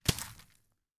Christmas Sound Effects #1
026 snowball hit.wav